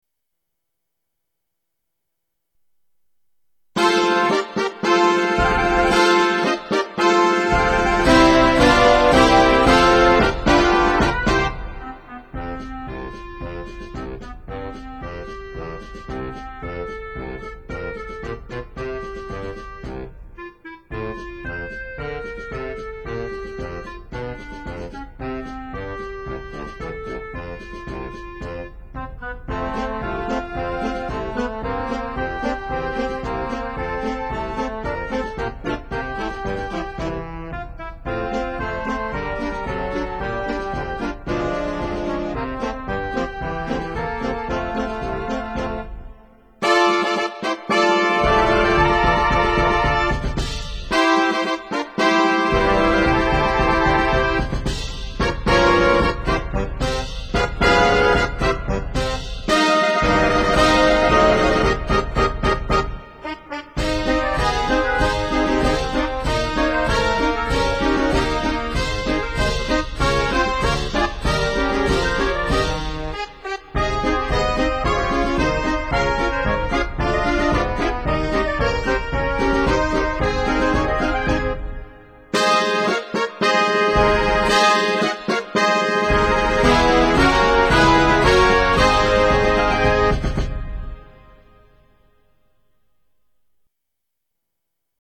Style: Concert March
Instrumentation: Standard Concert Band